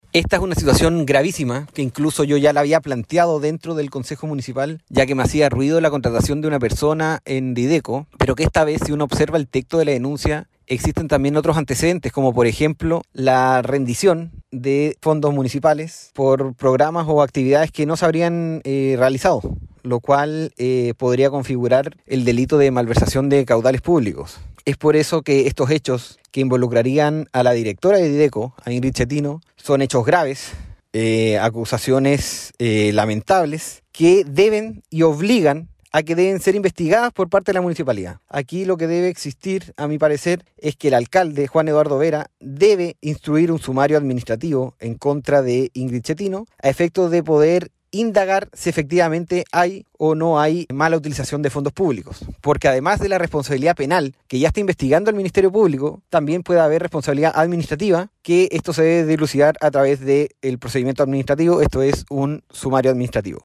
Respecto a la denuncia por el presunto delito de malversación de fondos público se refirió el Concejal de Castro Ignacio Álvarez Vera: